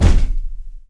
FEETS 1   -L.wav